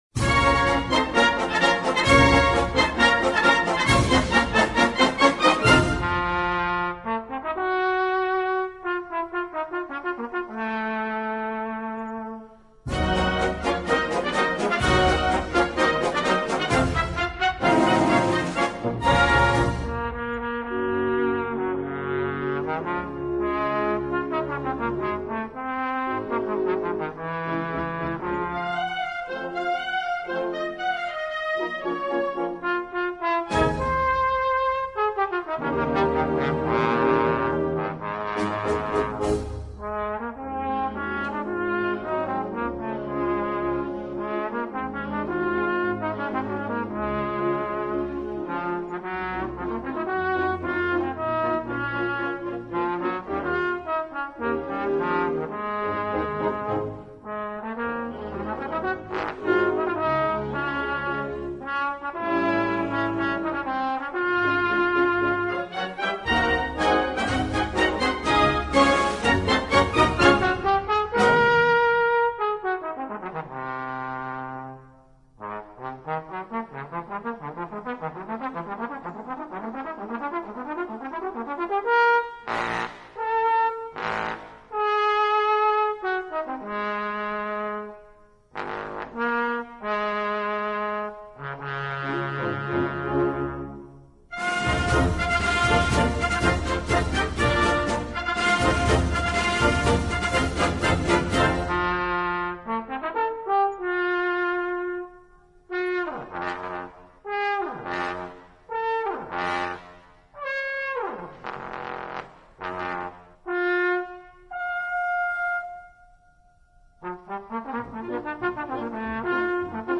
Voicing: Trombone w/ Band